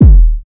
SouthSide Kick Edited (14).wav